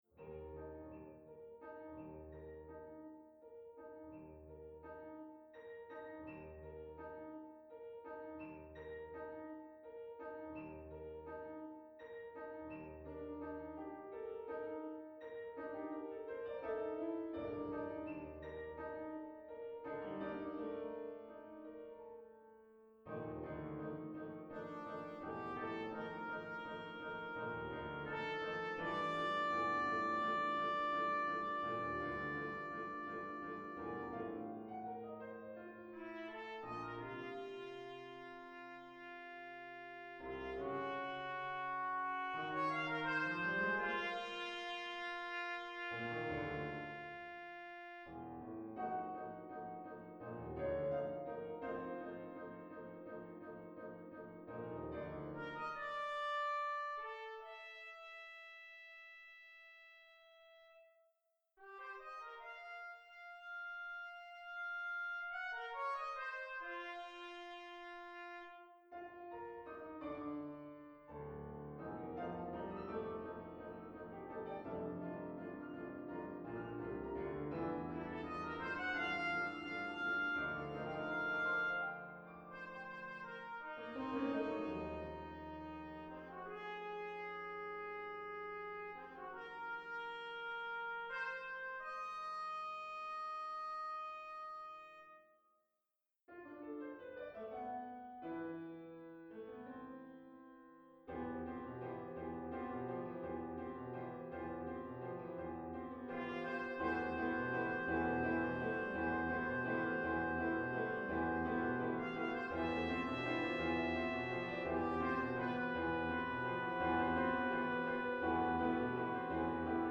Here is an mp3 of a computer generated version of Allan Rae's Concerto for Trumpet 'Tú hablas muy bien' (1996) as arranged for trumpet and two pianos, by A N Other: